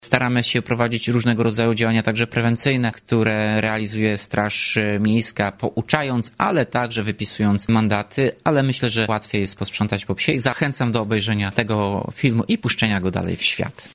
Mówił Tomasz Andrukiewicz, prezydent Ełku.